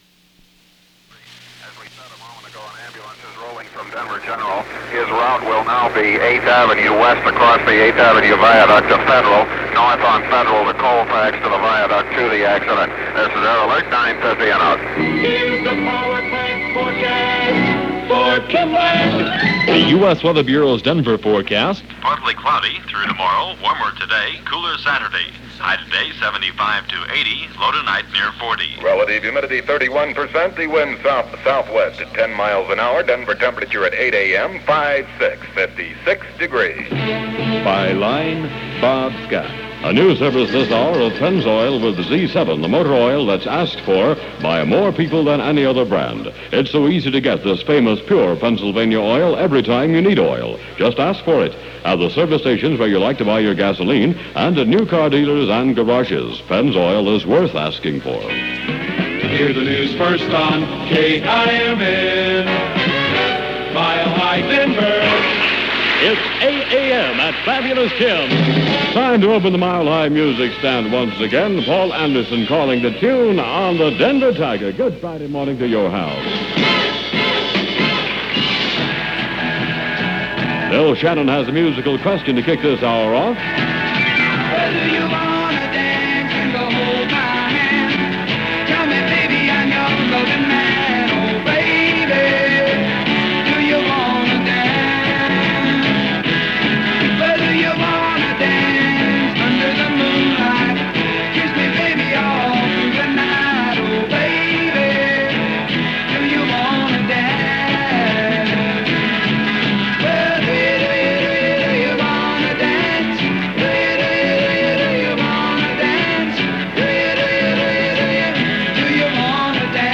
There was a time when Top-40 Radio was the be-all/end-all for growing up – it was the place that offered comfort, good times, nursed heartaches, celebrated first meetings; it was the clearing house for creating impressions that stuck with us for years after.